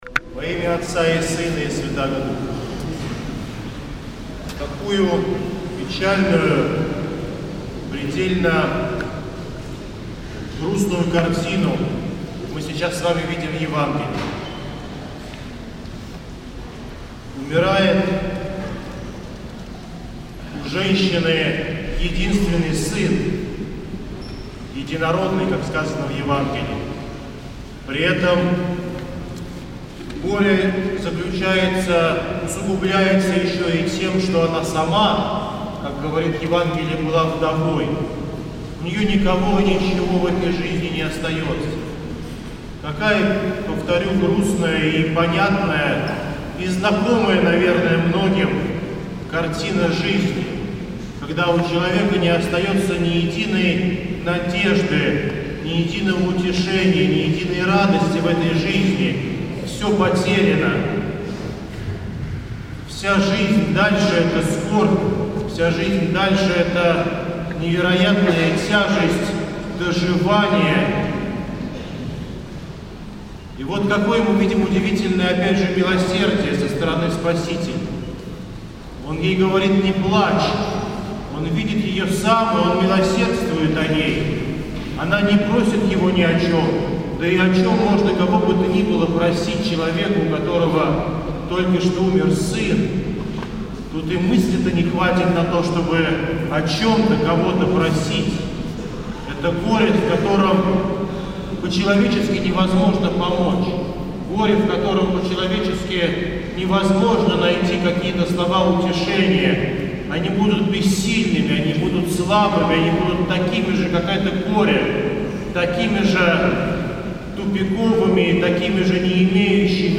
проповедь на поздней Литургии